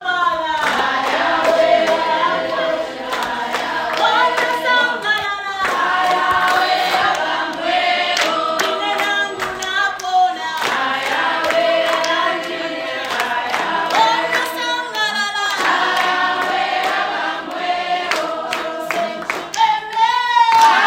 We ended our day by joining together with the families and children in the ward for a time of fellowship.
Ward Fellowship Praise & Worship - Click here to listen